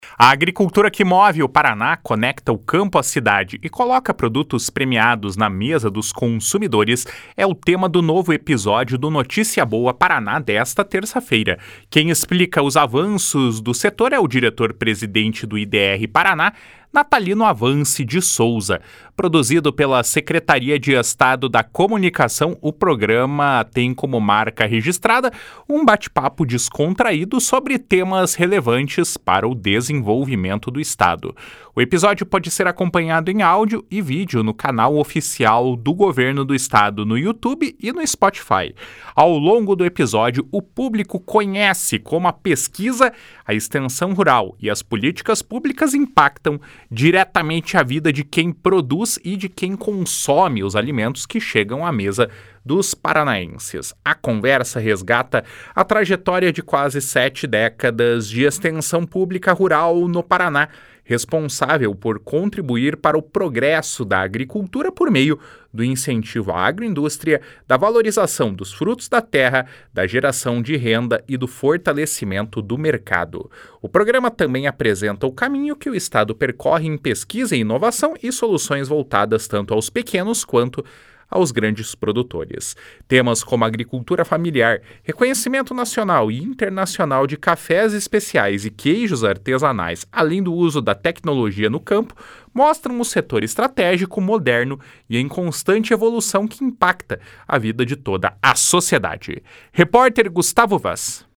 A agricultura que move o Paraná, conecta o campo à cidade e coloca produtos premiados na mesa dos consumidores é o tema do novo episódio do Notícia Boa Paraná desta terça-feira. Quem explica os avanços do setor é o diretor-presidente do IDR-Paraná, Natalino Avance de Souza. Produzido pela Secretaria de Estado da Comunicação, o programa tem como marca registrada um bate-papo descontraído sobre temas relevantes para o desenvolvimento do Estado.